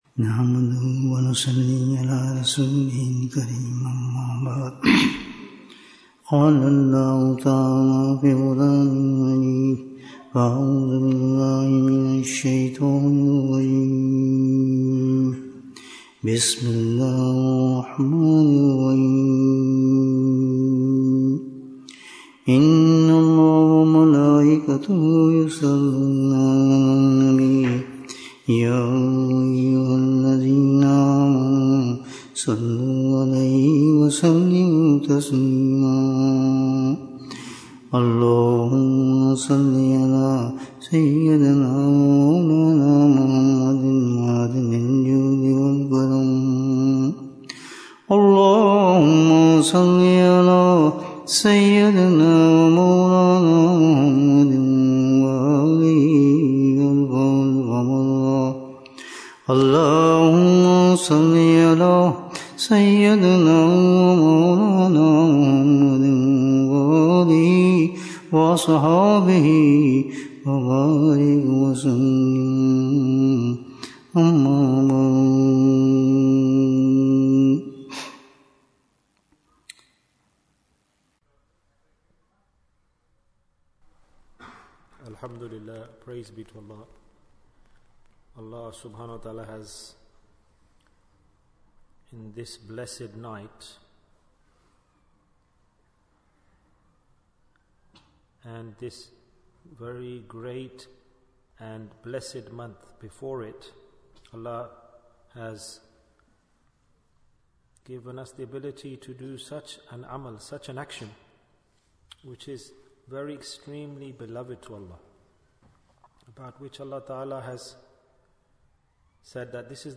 Save Yourself From the Fitna of Dajjal Bayan, 39 minutes4th July, 2024